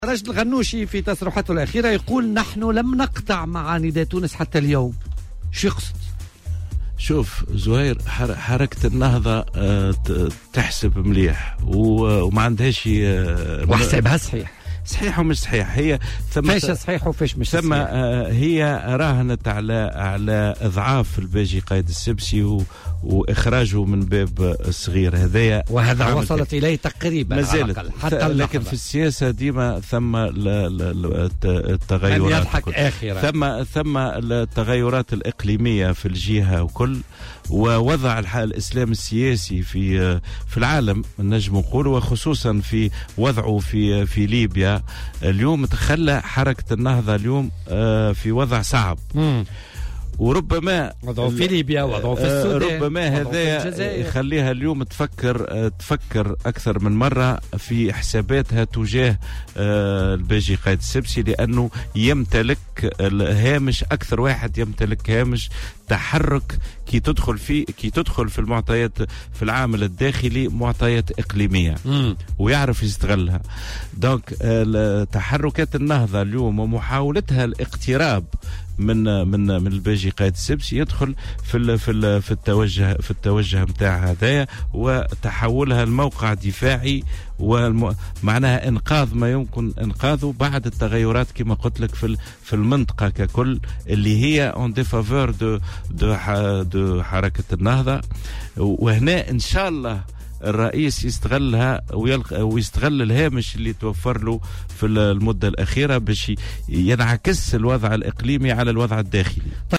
وأضاف بالحاج، ضيف برنامج "بوليتيكا" اليوم الثلاثاء : "حركة النهضة "تحسب مليح" وراهنت على إضعاف الباجي قائد السبسي وإخراجه من الباب الصغير، لكنها لم تنجح في ذلك..وفي ظل التغيرات الاقليمية ووضع الاسلام السياسي في العالم، أدركت أن وضعها أصبح صعبا و أعادت حساباتها تجاه علاقتها بالباجي قائد السبسي لإنقاذ ما يمكن إنقاذه".